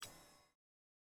sfx-exalted-hub-summon-x1-hover.ogg